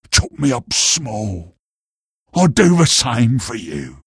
Worms speechbanks
Byebye.wav